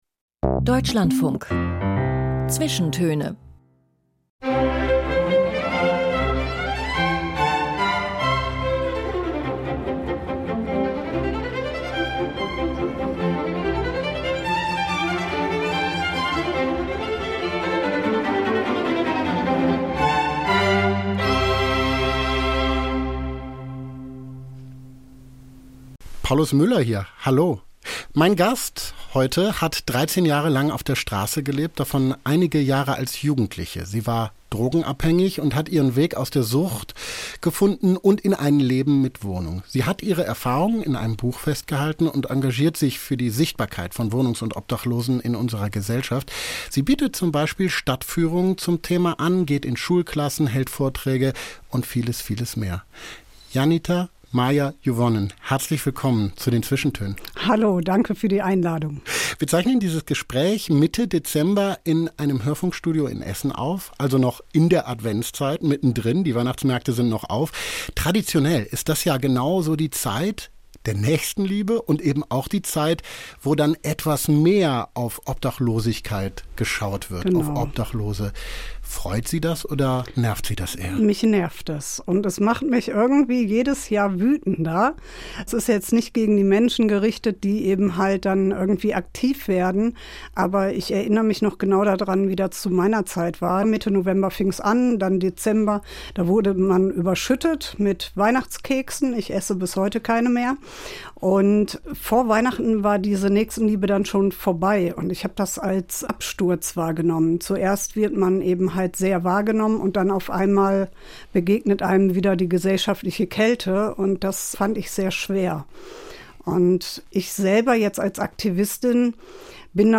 Die „Zwischentöne“ laden zum persönlichen Gespräch ein. Gäste aus Kultur, Wissenschaft und Gesellschaft erzählen anderthalb Stunden lang von ihrem Schaffen und aus ihrem Leben, von Begegnungen und Erlebnissen, die sie geprägt haben, von wichtigen Entscheidungen, Erfolgen und Niederlagen. Der Titel der Sendung ist Programm: kein krachender Schlagabtausch, sondern ein feinsinniges Gespräch, das auch von den mitgebrachten Titeln lebt. Sie sind nicht nur Begleitmusik, sondern Anlass, die Gäste von einer weiteren Seite kennenzulernen.